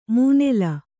♪ mūnela